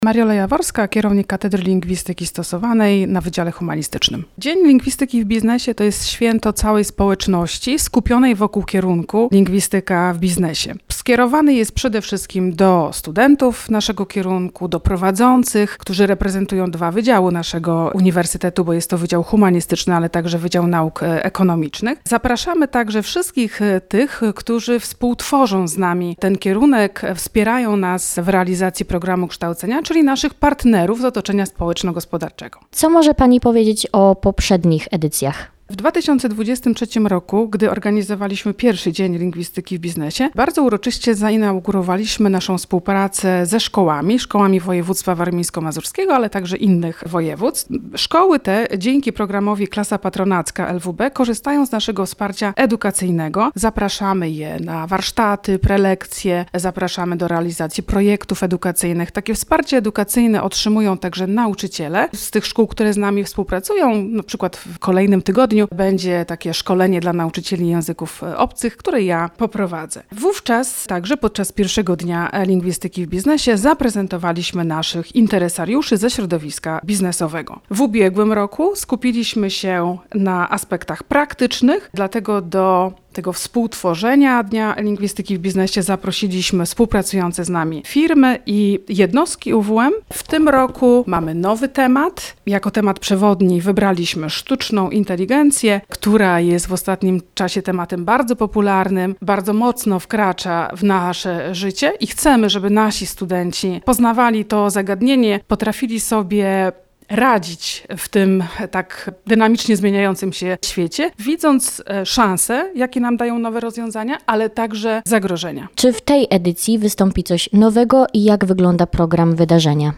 Posłuchajcie rozmowy naszej reporterki